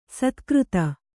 ♪ satkřta